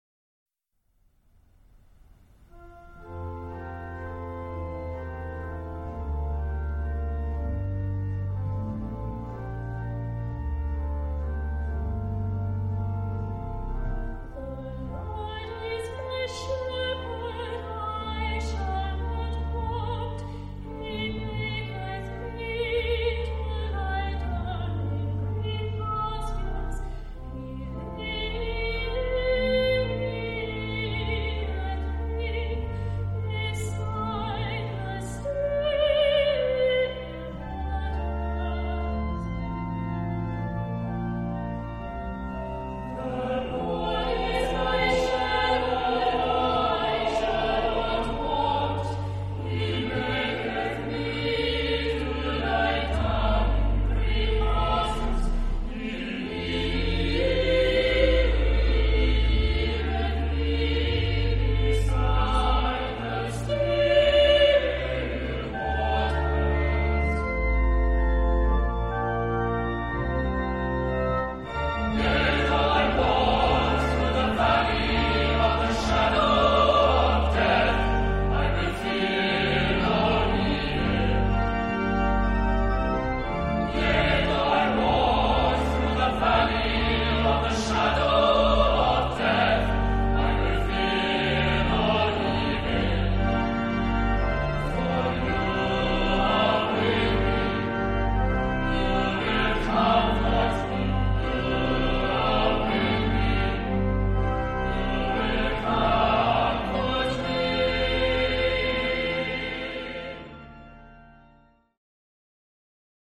Genre-Style-Form: Sacred ; Choir ; Psalm
Type of Choir: SATB  (4 mixed voices )
Soloist(s): Soprano (1)  (1 soloist(s))
Instrumentation: Organ or Piano  (1 instrumental part(s))
Tonality: F major